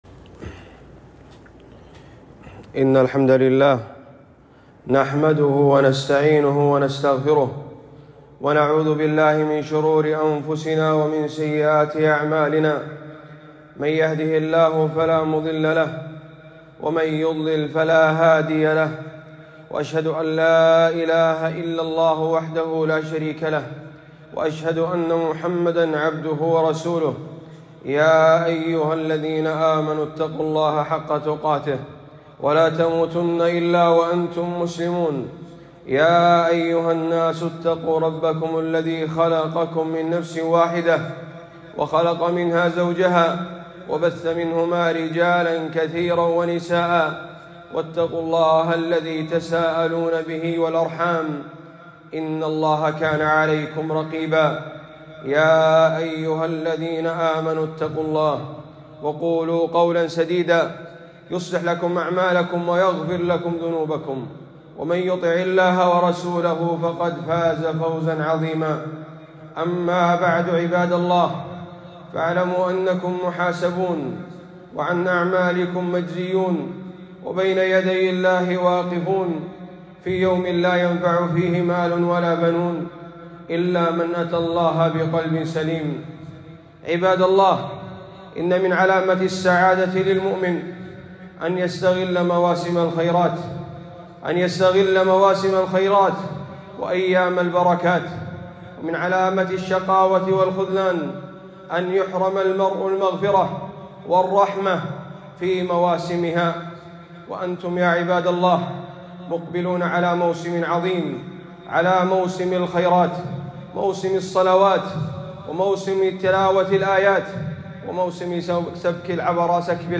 خطبة - بين يدي شهر رمضان ٢٧ شعبان ١٤٤٥ هـ